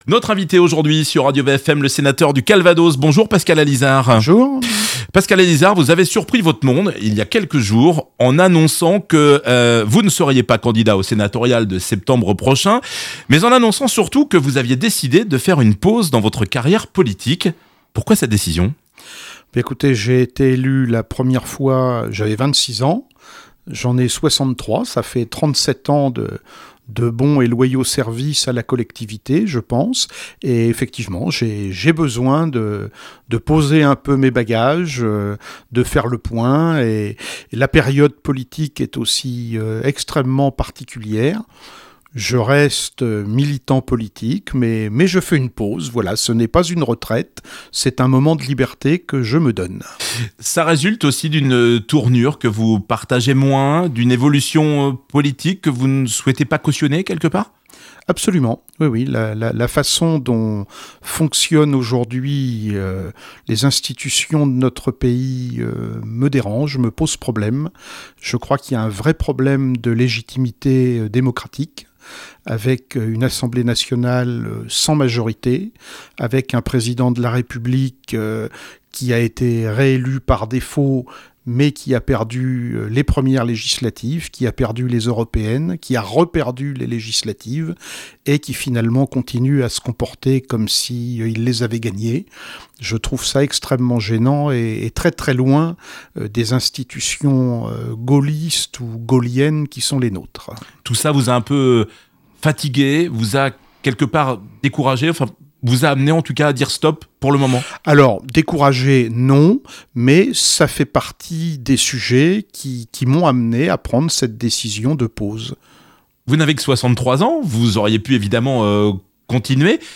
Interview de Pascal Allizard
14 min 37 sec Interview de Pascal Allizard Le sénateur du Calvados Pascal Allizard invité de Radio VFM ! Sa volonté de marquer une pause dans sa carrière politique en ne se représentant pas aux sénatoriales, ce qu’il retient de ses nombreux mandats d’élu, sa position sur les municipales à Conde en Normandie , ses projets pour l’avenir…